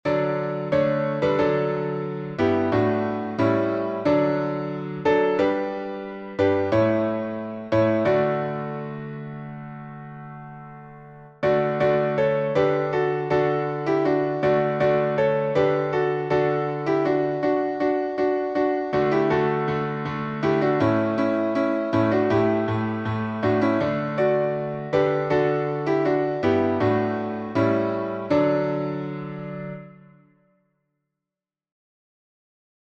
Joy to the World — alternate chords.